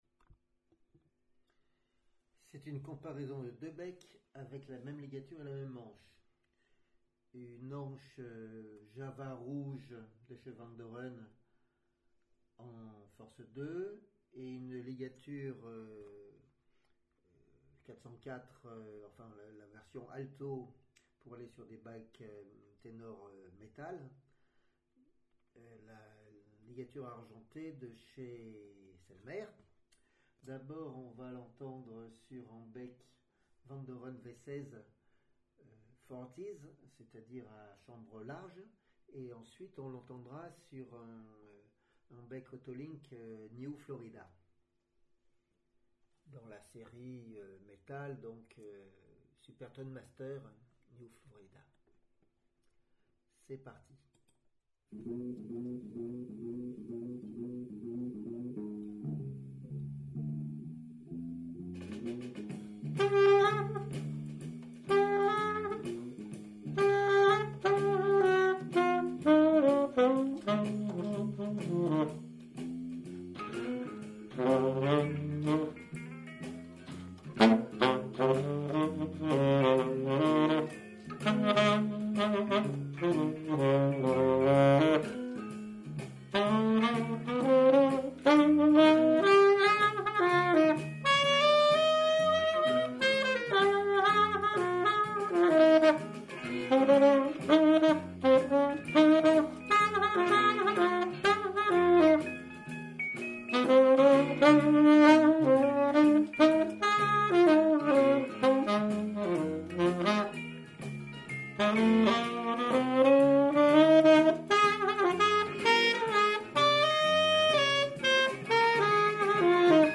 2. Tenor mouthpieces and reeds comparisons,